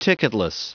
Prononciation du mot ticketless en anglais (fichier audio)
Prononciation du mot : ticketless